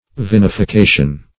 Vinification \Vin`i*fi*ca"tion\, n. [L. vinum wine + E.
vinification.mp3